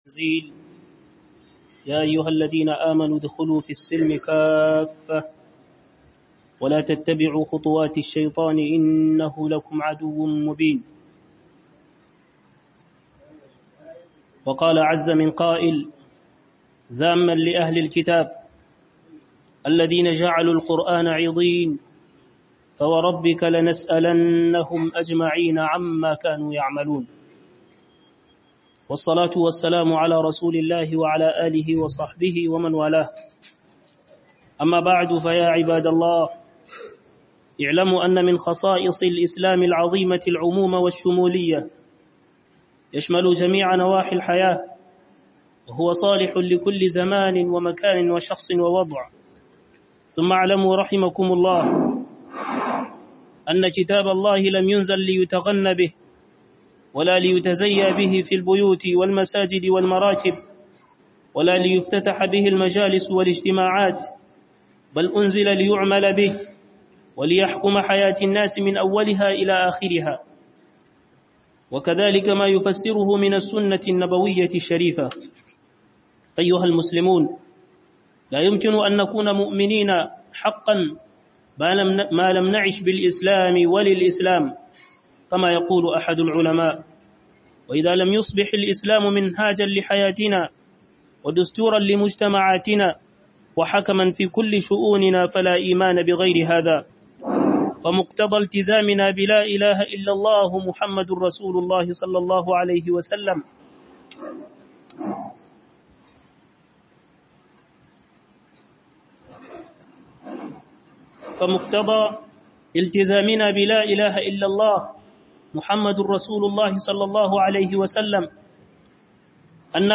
Huduba